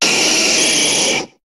Cri de Nidoking dans Pokémon HOME.